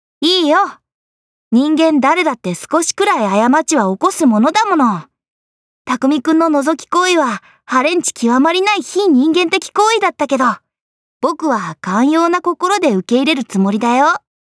cha11yuki_voice_sample.mp3